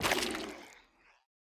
Minecraft Version Minecraft Version 1.21.5 Latest Release | Latest Snapshot 1.21.5 / assets / minecraft / sounds / block / sculk / break6.ogg Compare With Compare With Latest Release | Latest Snapshot
break6.ogg